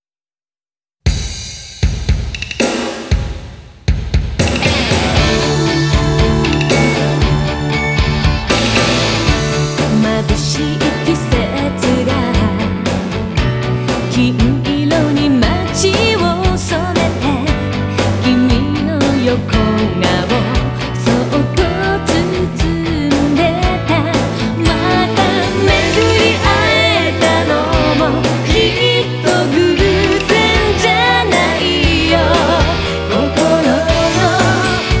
Жанр: Поп / J-pop